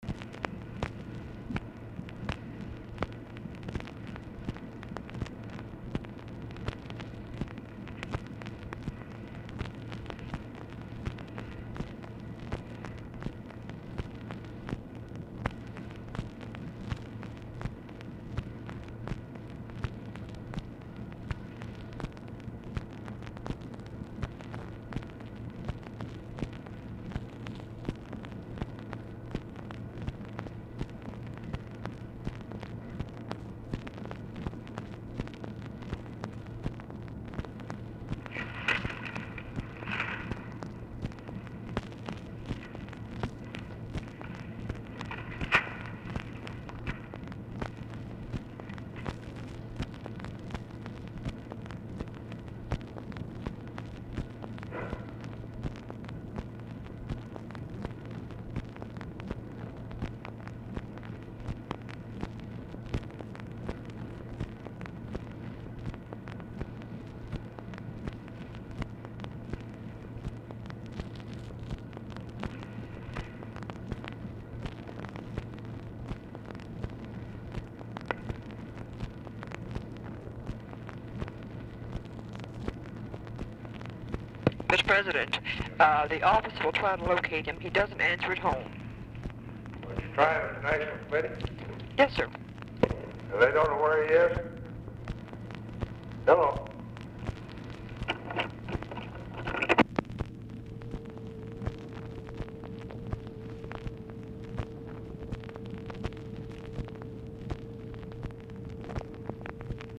Telephone conversation # 4934, sound recording, LBJ and TELEPHONE OPERATOR, 8/14/1964, time unknown | Discover LBJ
LBJ ON HOLD 1:22; OFFICE NOISE PRECEDES CALL
Format Dictation belt
Oval Office or unknown location